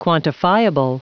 Prononciation du mot quantifiable en anglais (fichier audio)
Prononciation du mot : quantifiable